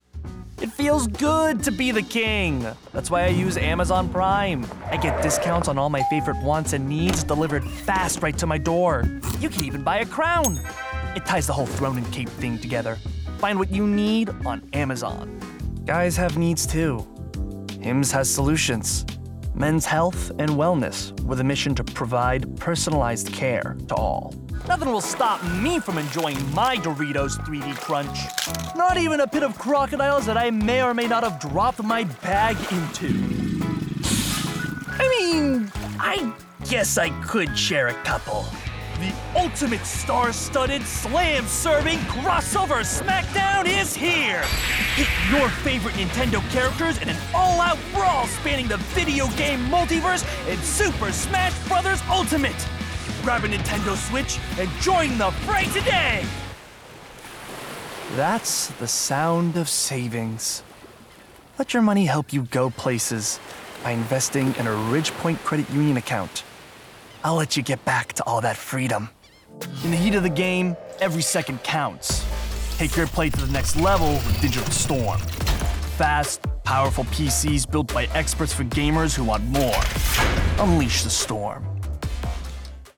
Commercial Reel
Rode NT1
Hardwood booth treated with:
4 96x80 acoustic blankets